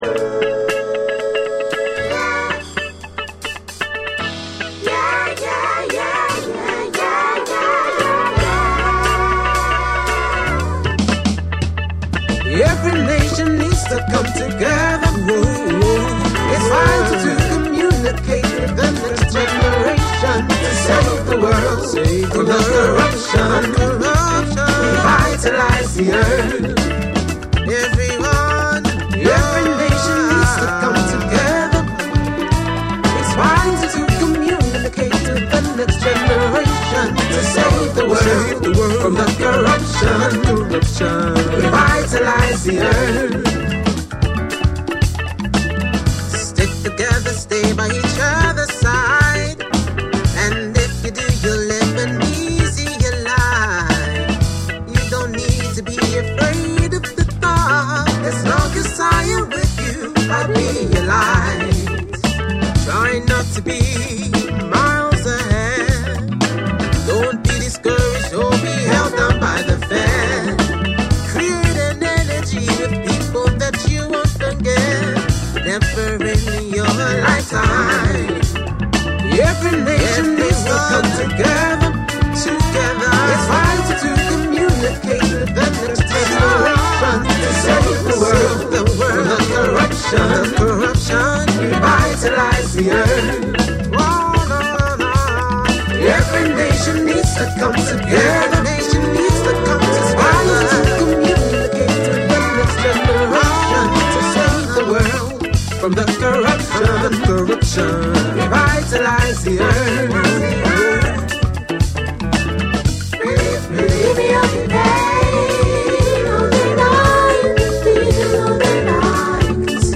BREAKBEATS / SOUL & FUNK & JAZZ & etc